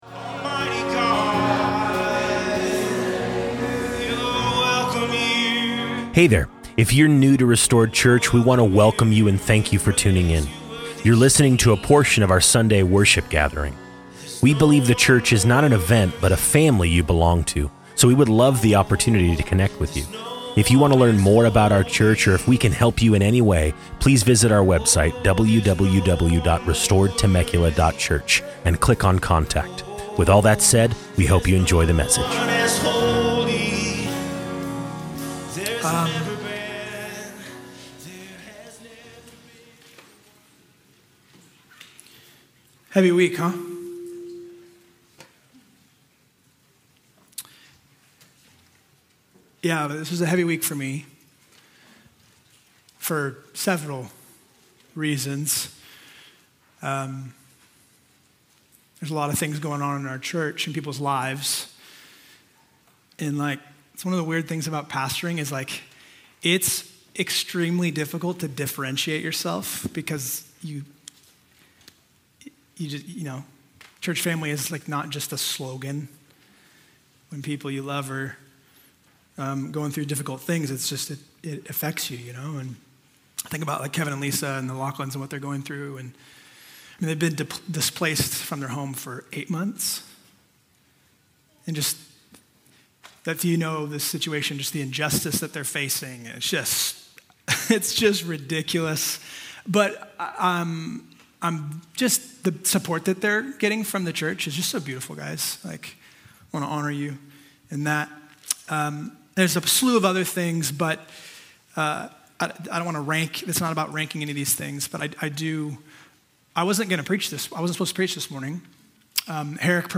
Pastoral Address on Charlie Kirk